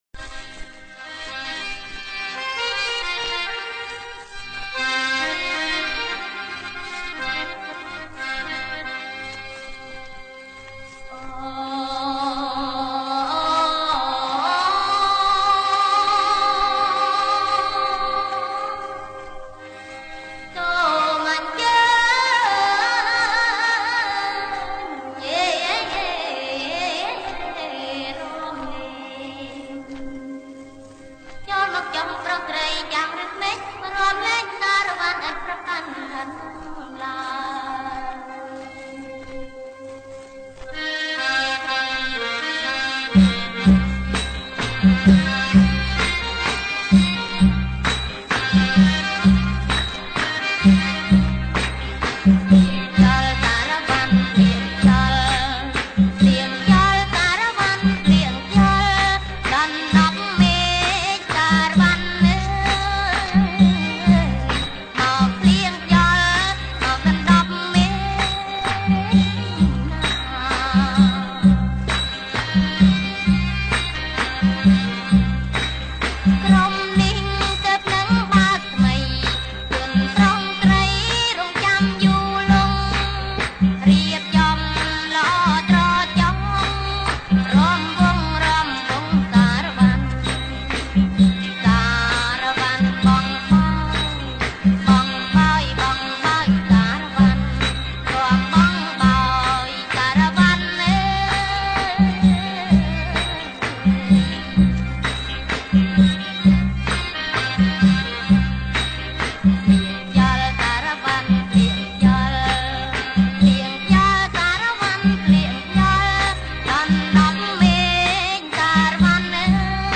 • ប្រគំជាចង្វាក់ សារ៉ាវ៉ាន់